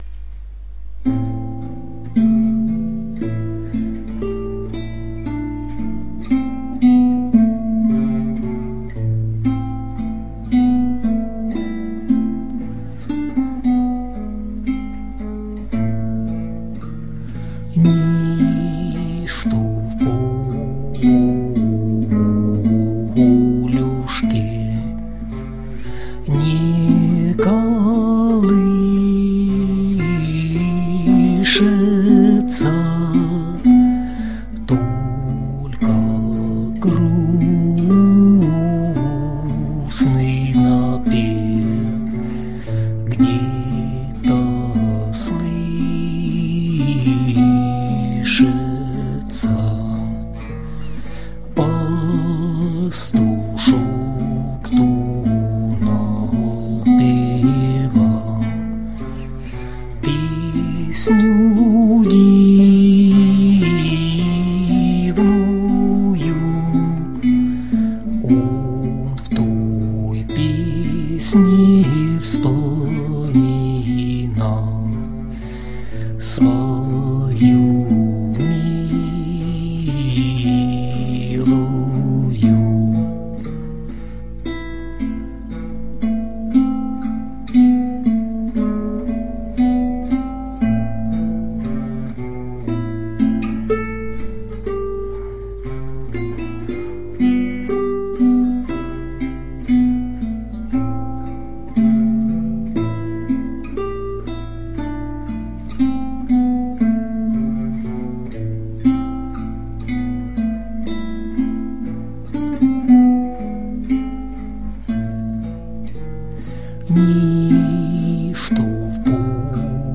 ../icons/nichyaka.jpg   Русская народная песня